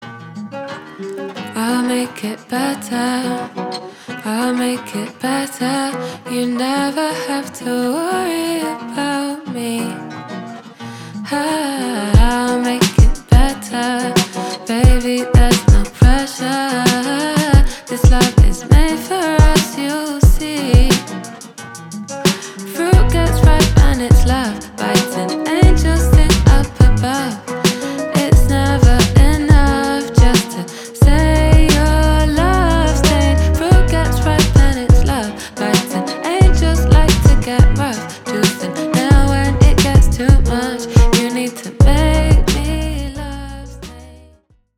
поп
красивые
женский вокал
RnB
alternative
soul